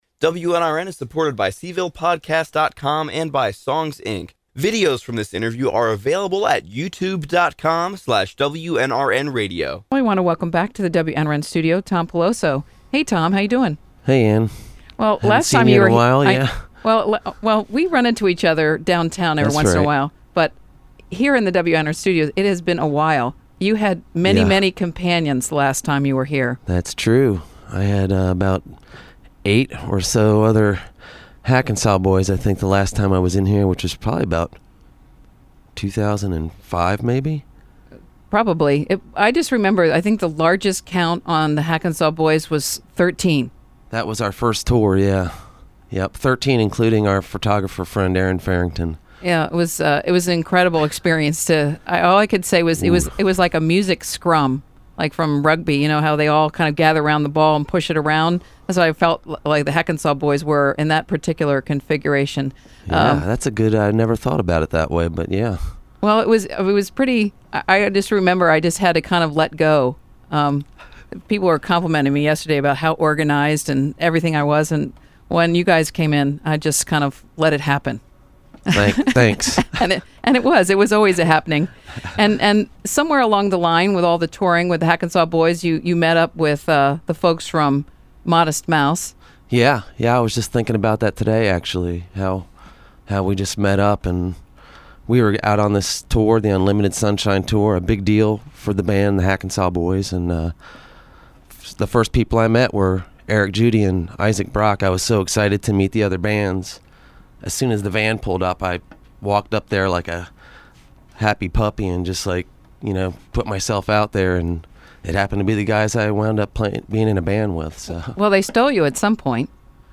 He also played a couple of songs.